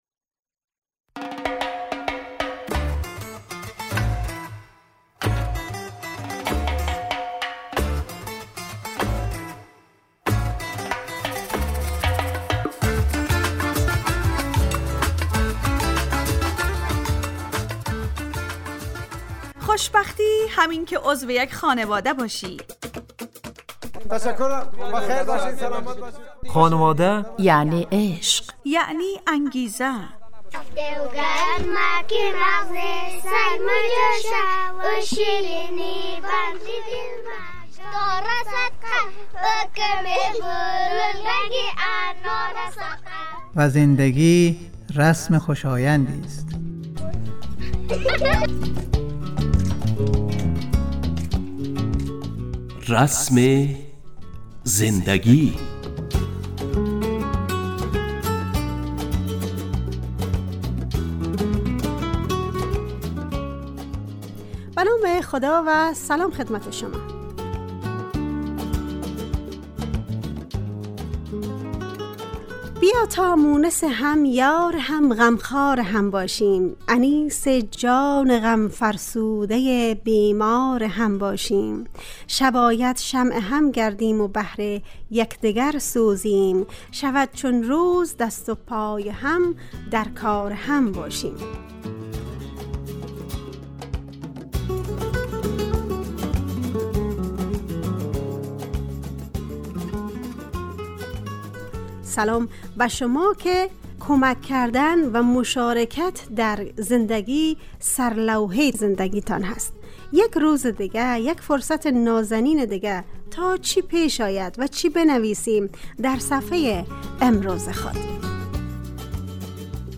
رسم زندگی_ برنامه خانواده رادیو دری ___ یکشنبه 22 تیر 404 ___موضوع_ مشارکت و همکاری _ گوینده و تهیه کننده و میکس